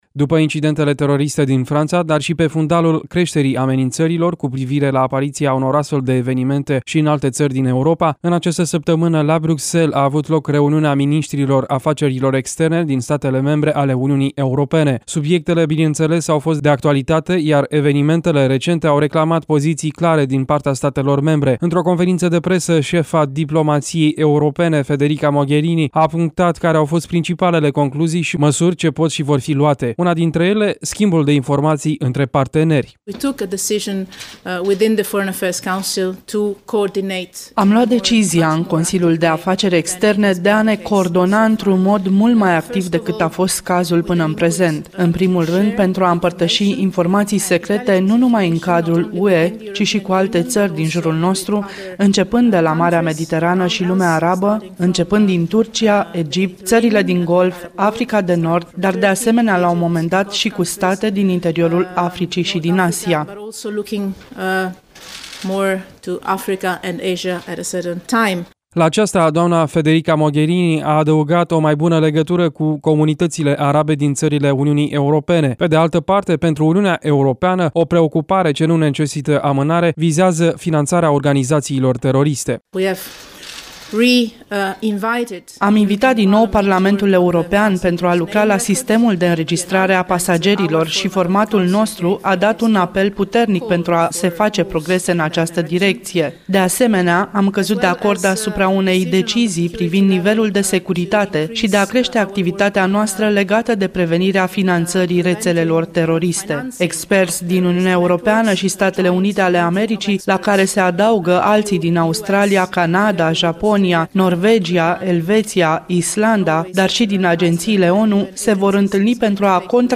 Într-o conferință de presă șefa diplomației europene, doamna Federica Mogherini a punctat care au fost principalele concluzii și măsuri ce pot și vor fi luate.